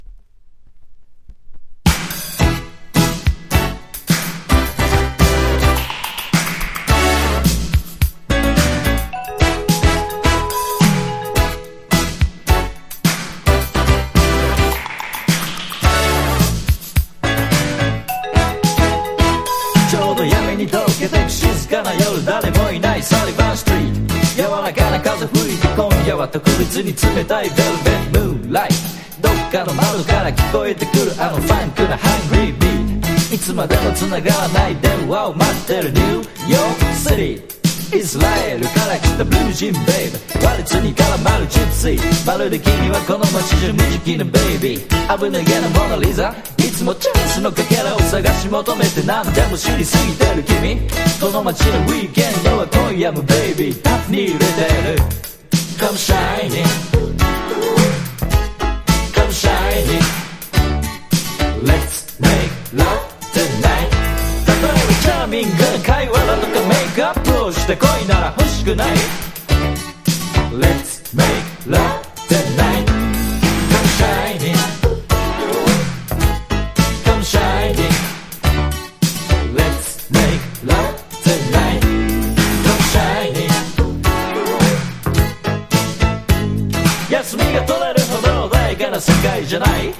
当時まだ珍しかったラップをいち早く取り入れた日本語ラップの初期作とも言える歴史的名曲！